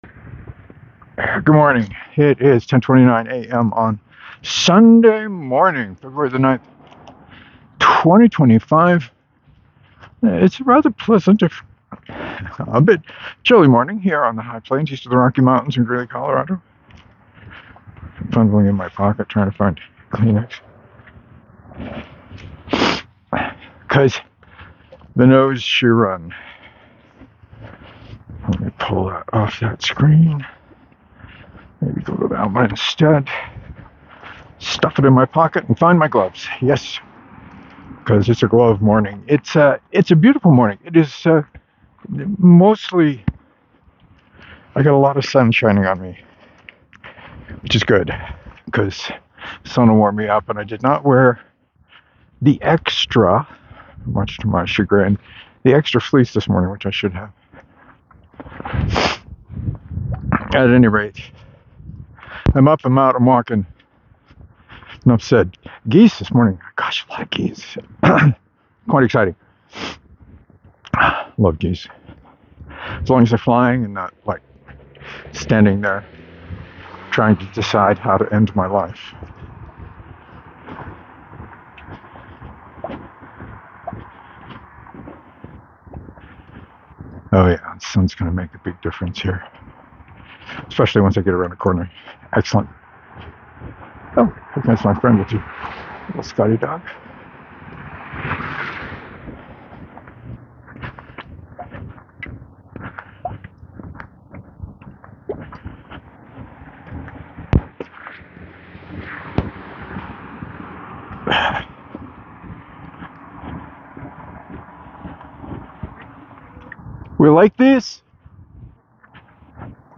Chilly morning that started with lots of geese. Talked about Gabriel Rathweg’s From Chef to Crafter to Conqueror, my current read.